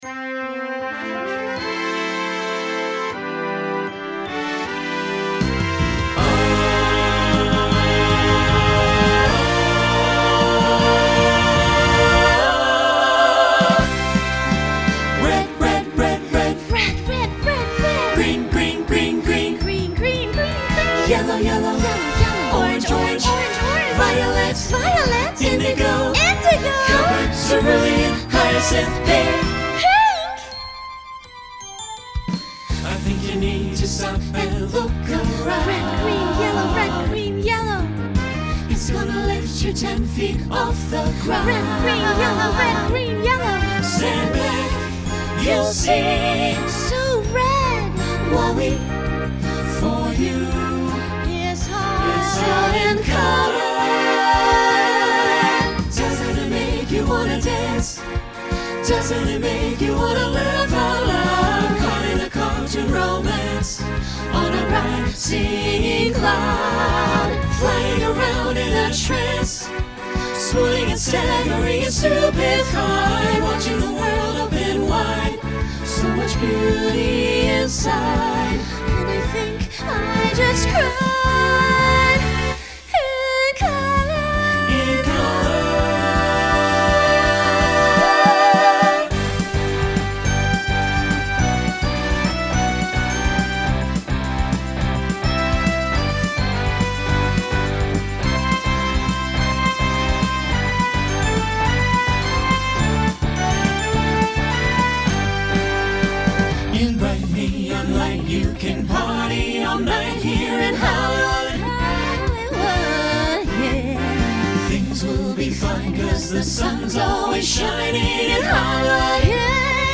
Genre Broadway/Film Instrumental combo
Transition Voicing SATB